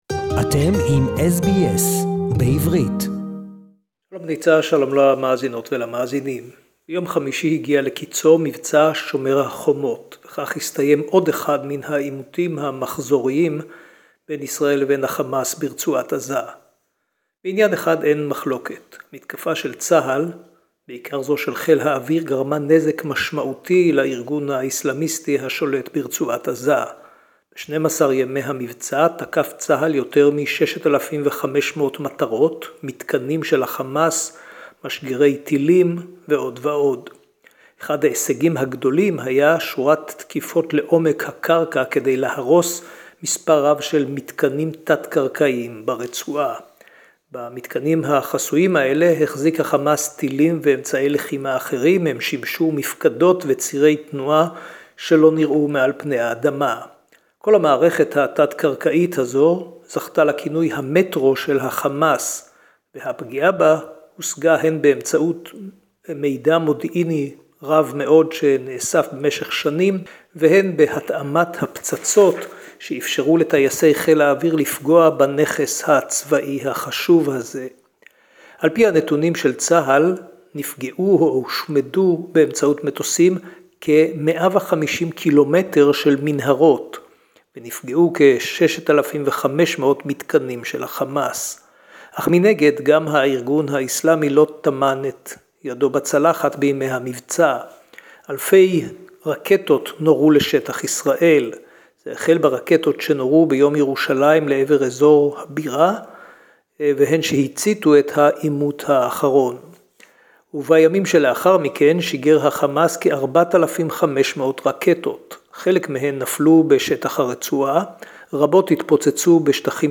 A ceasefire between Israel and Hamas, ending 11 days of intensive fighting...SBS Jerusalem report